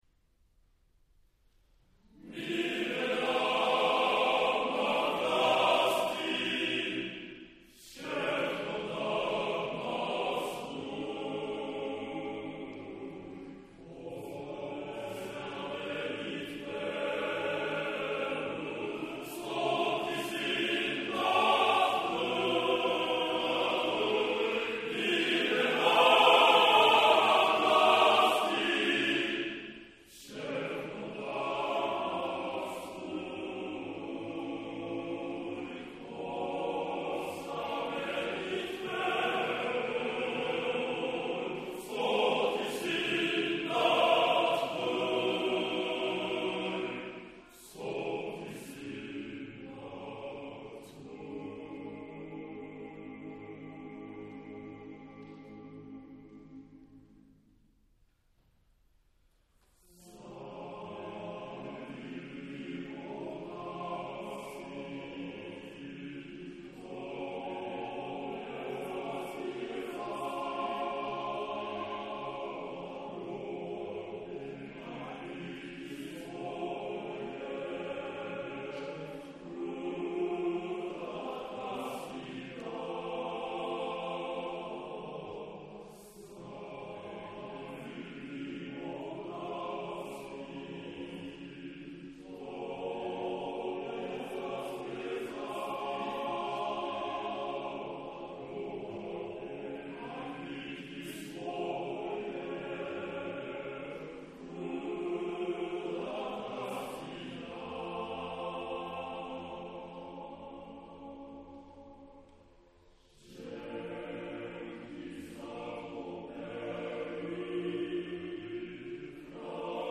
■録音日：2001年10月6日　■録音場所：北とぴあ（東京都北区）　さくらホール
ピアノ
「わが祖国」は、祖国からの贈り物である美しい自然や産物への感謝と歓びが、全く無駄のない音の配列による旋律感と躍動感で表現される。